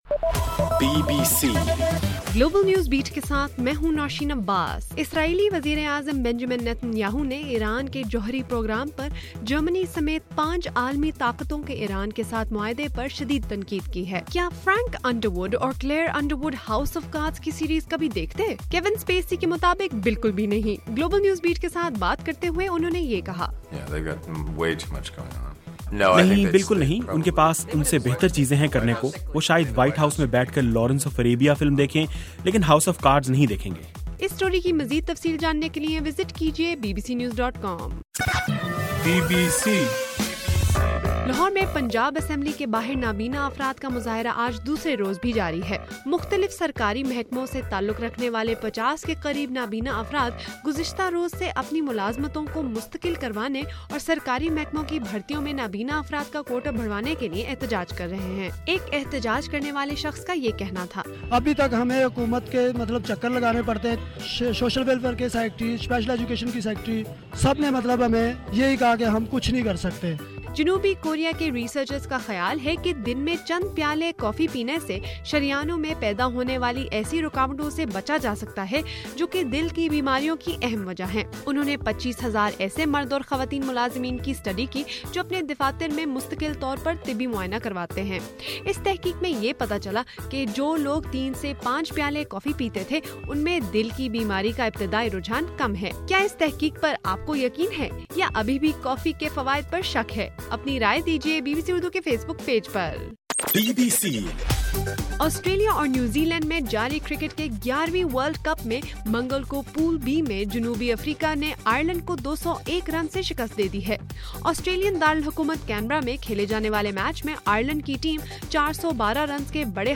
مارچ 3: رات 12 بجے کا گلوبل نیوز بیٹ بُلیٹن